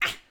SFX_Battle_Vesna_Attack_06.wav